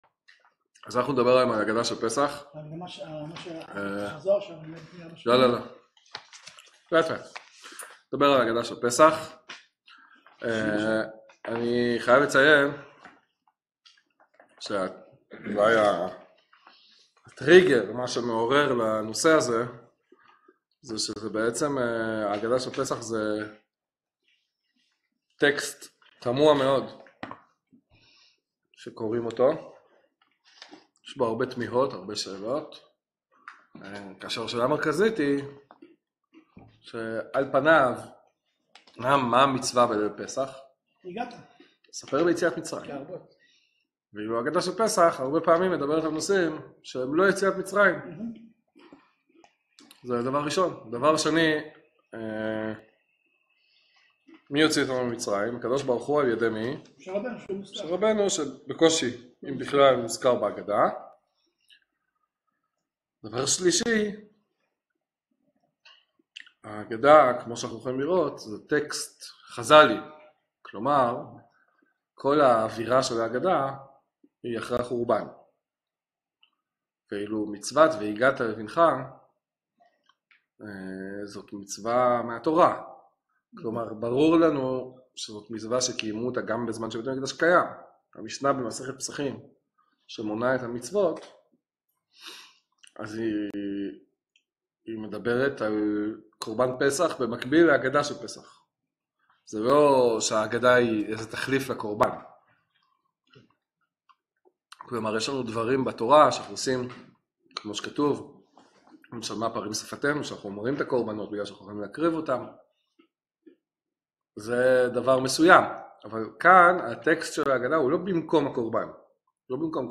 שיעור מעמיק החושף את סוד ההגדה ואת הכוונה האמתית שלה!